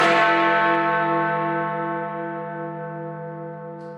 big_ben_single_bong.mp3